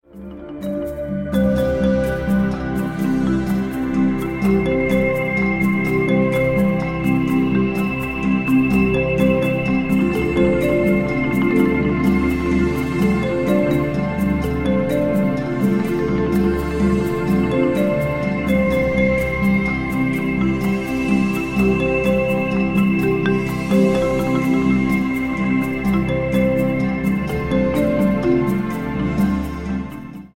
84 BPM
Medium tempo marimba ostinato over swirling atmospheres.